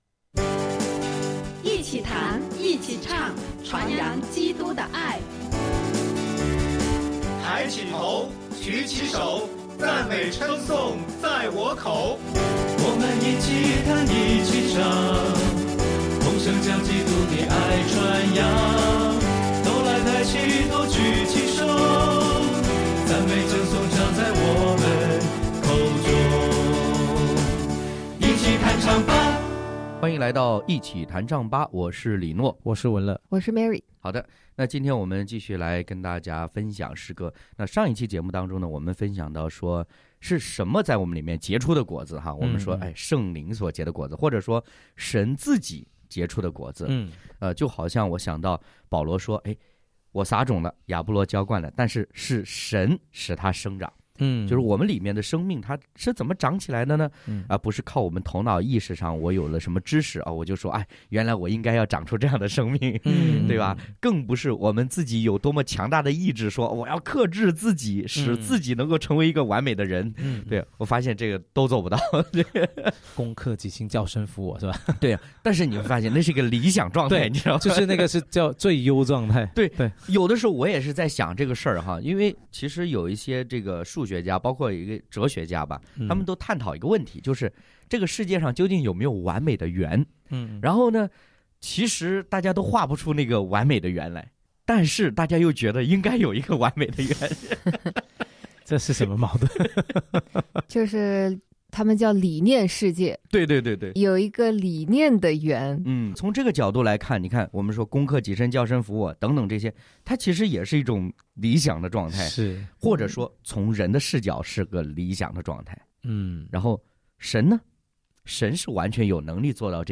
敬拜分享：立定心志，成就果子；诗歌：《将一生交给祢》、《盟约》